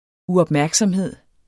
Udtale [ uʌbˈmæɐ̯gsʌmˌheðˀ ]